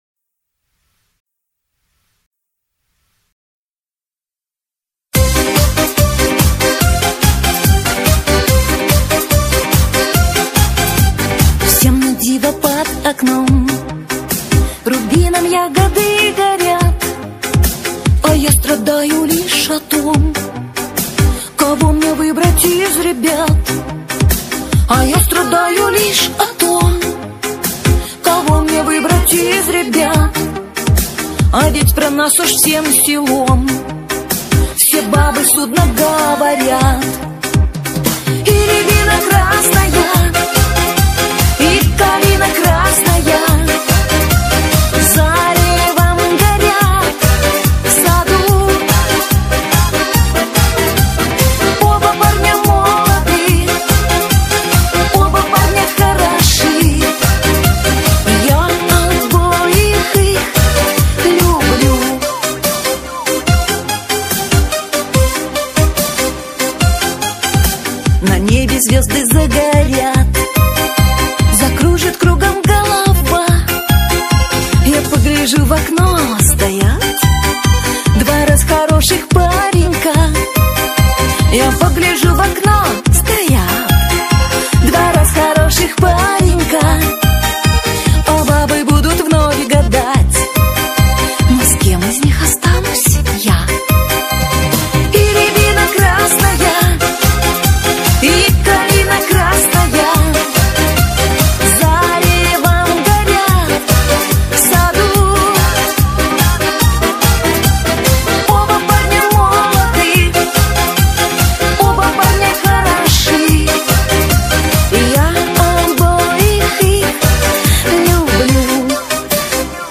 песня ритмичная